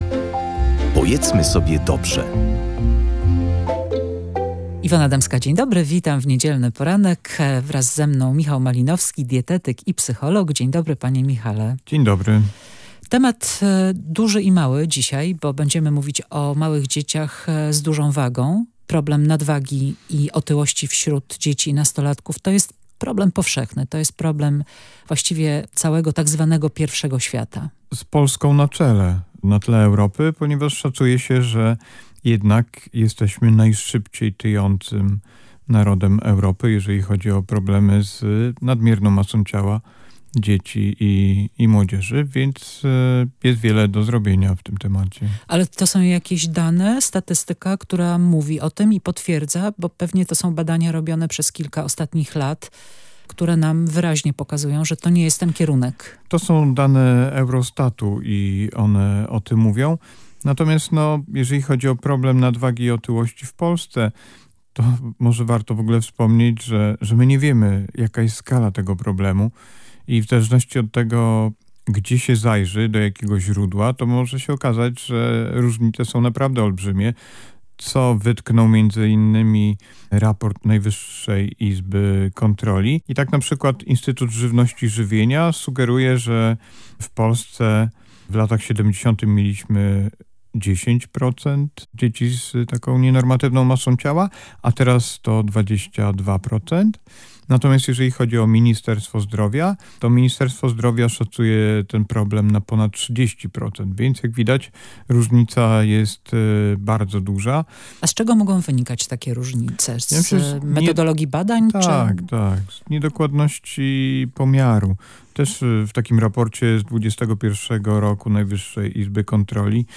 rozmawiała z dietetykiem i psychologiem